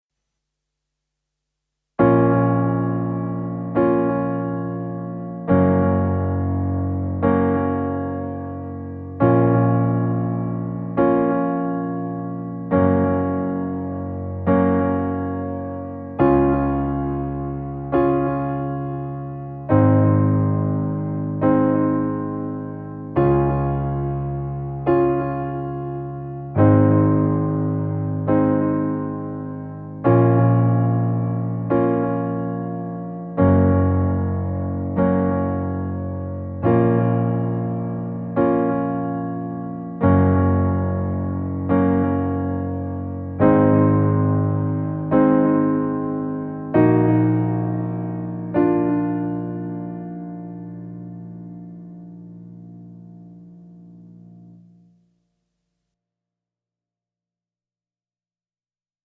da ich von der band isoliert bin....mache ich alleine musik, so im vorbeigehen im proberaum entstanden....fehlerbehaftet...